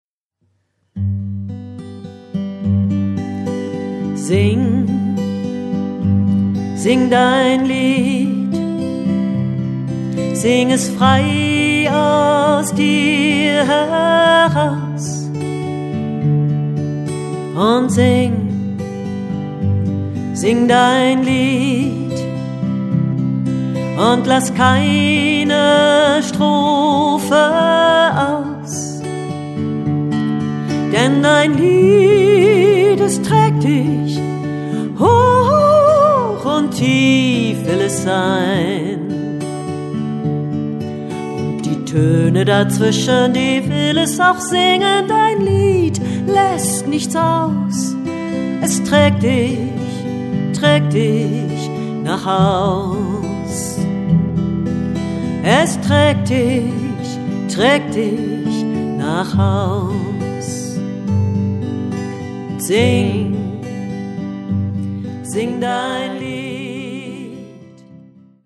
Schwungvolle, meditative  und einfuehlsame Lieder und Gebete